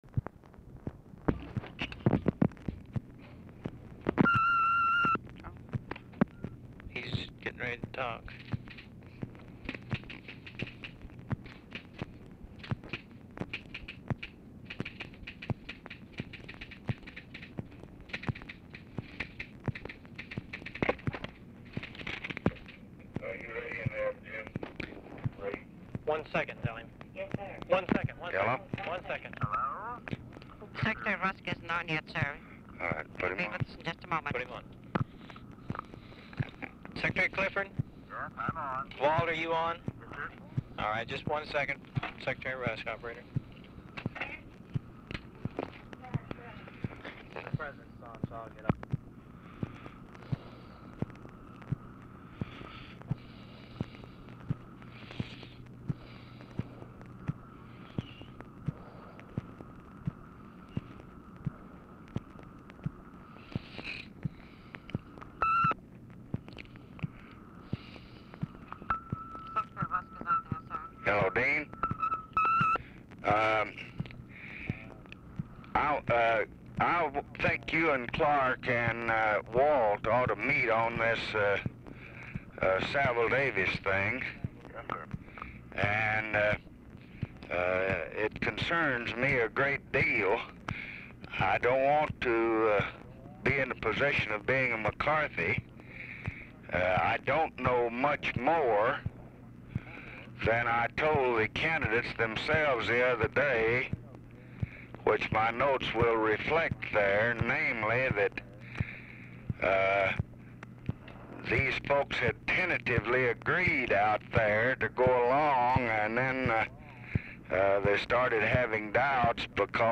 Telephone conversation # 13713, sound recording, CONFERENCE CALL (with LBJ), 11/4/1968, 12:27PM
Format Dictation belt
Location Of Speaker 1 LBJ Ranch, near Stonewall, Texas
Other Speaker(s) CLARK CLIFFORD, DEAN RUSK, WALT ROSTOW, JIM JONES, TELEPHONE OPERATORS